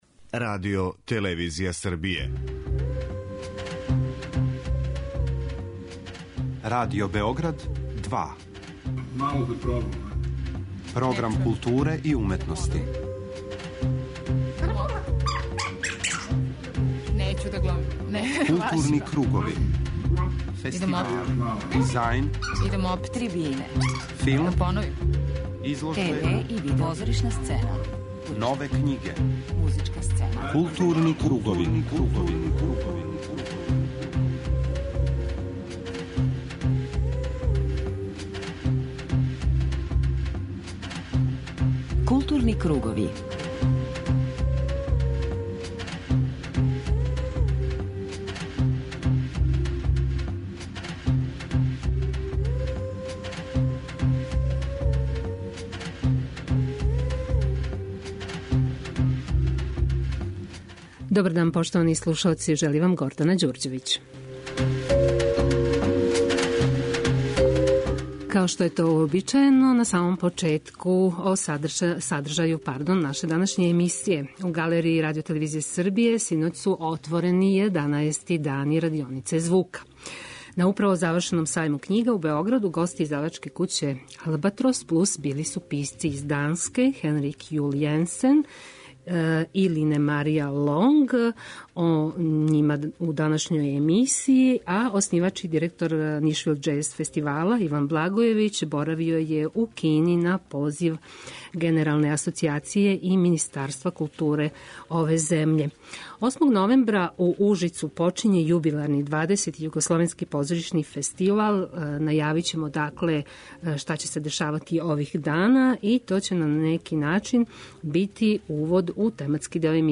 преузми : 41.54 MB Културни кругови Autor: Група аутора Централна културно-уметничка емисија Радио Београда 2.